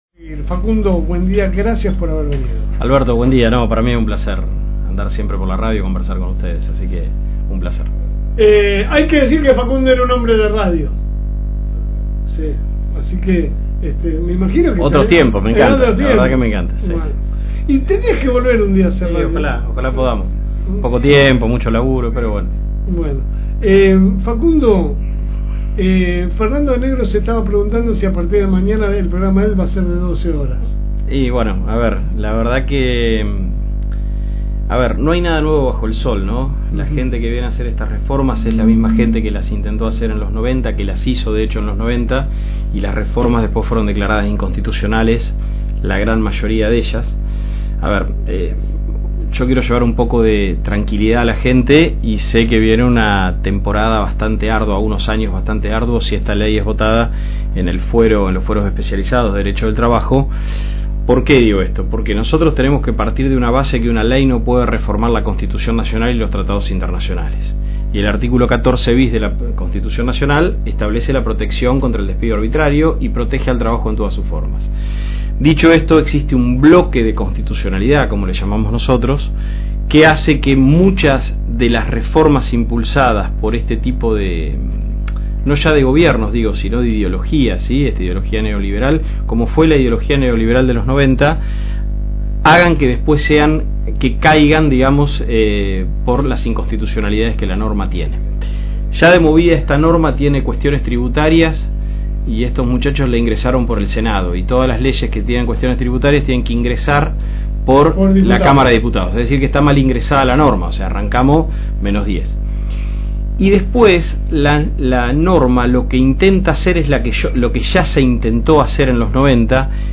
En conclusión, la entrevista puso de manifiesto las profundas diferencias de criterio en torno a la reforma.